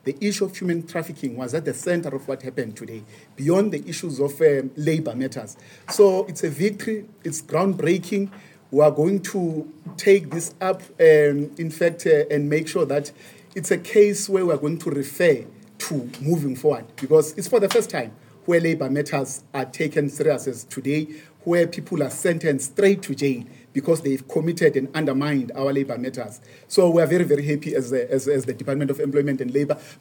Deputy Minister, Jomo Sibiya, said the ruling sends a strong message that South Africa will not tolerate exploitation and that the government remains committed to tackling illegal and unfair labor practices.